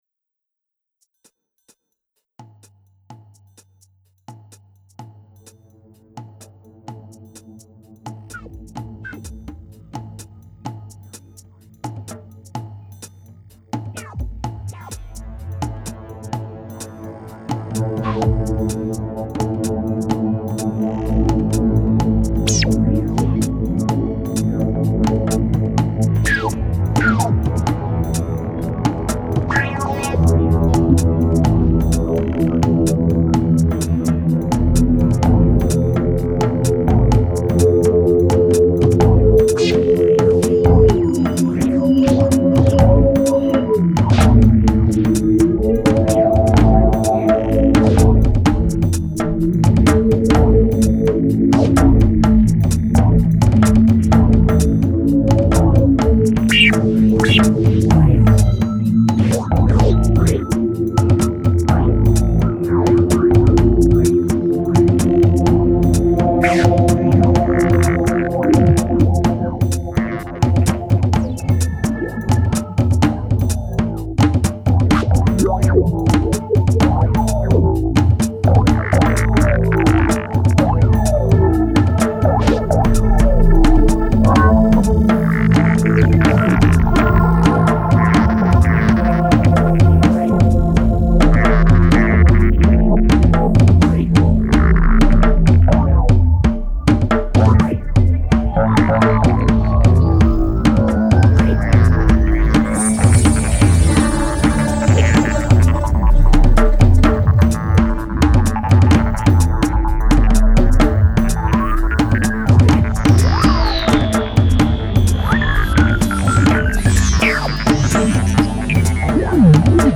Ambiante